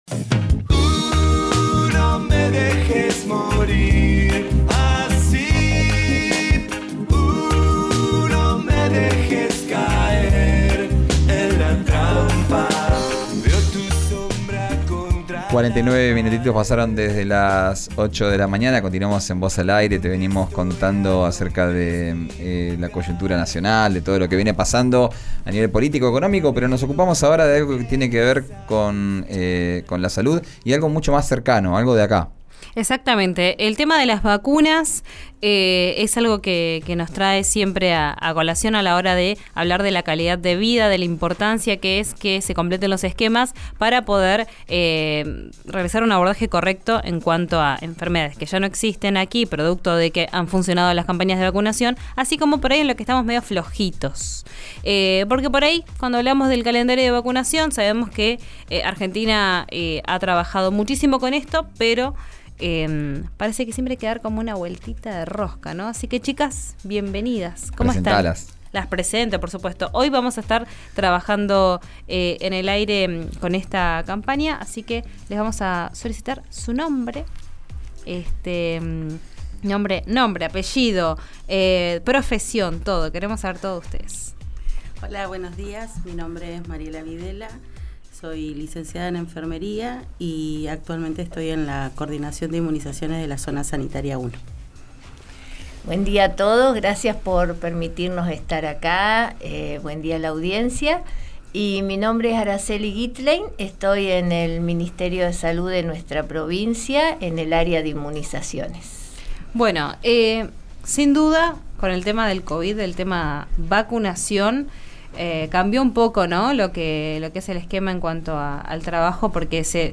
Escuchá la entrevista en RÍO NEGRO RADIO y conocé los horarios y establecimientos disponibles.